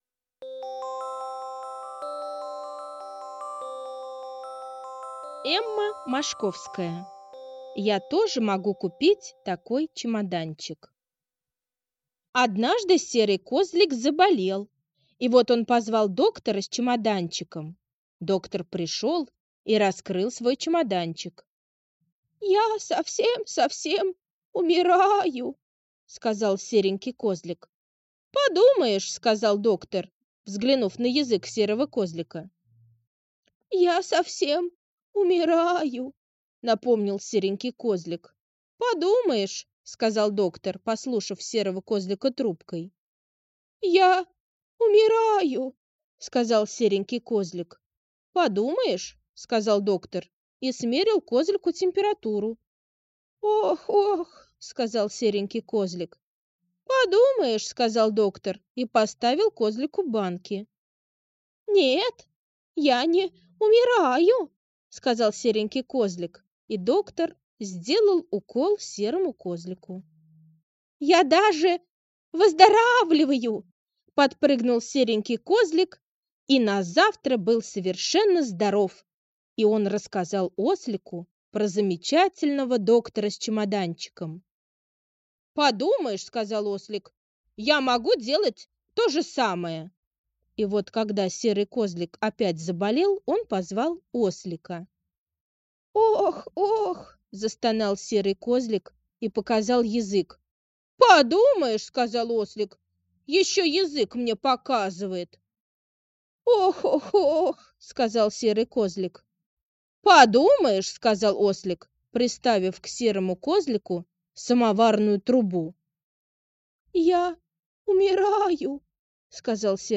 Я тоже могу купить такой чемоданчик — аудиосказка Мошковской Э. Однажды Серый Козлик заболел и к нему пришел Доктор с чемоданчиком...